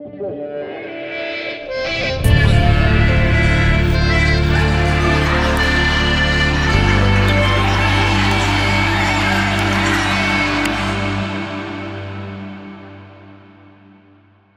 אתגר אקורדיון.wav
אז תוך צפייה בתוכנית אירוח אמריקנית, נתקלתי בקטע הזה: מישהי רנדומלית מהקהל עולה ומנגנת באקורדיון
מה שאהבתי פה הוא דווקא האקראיות - ניכר שהיא לא נגנית מקצוענית, לא יושבת בדיוק על הקצב, לא התאמנה, וגם יש רעש של קהל ברקע. חתכתי מזה 4 תיבות, סה"כ 2 אקורדים, אחלה אתגר…
אתגר אקורדיון.wav מהירות הקטע : כ-107BPM סולם : Dm - Am